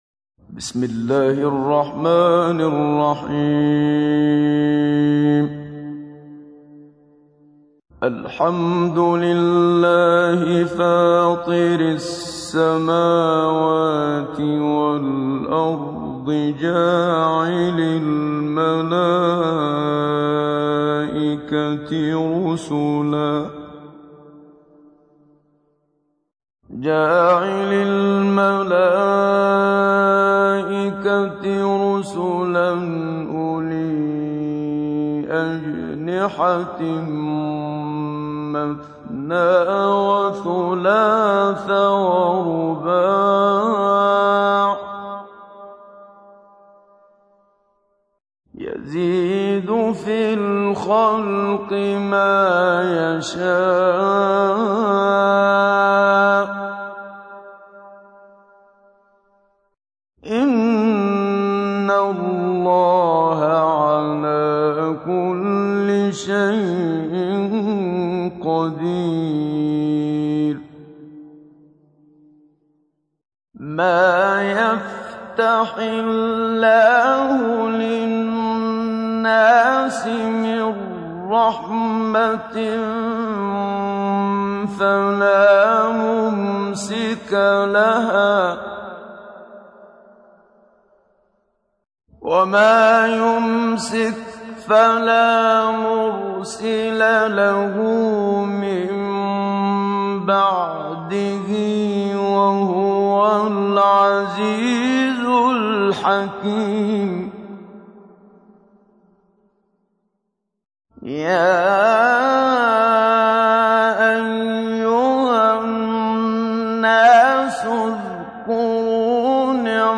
تحميل : 35. سورة فاطر / القارئ محمد صديق المنشاوي / القرآن الكريم / موقع يا حسين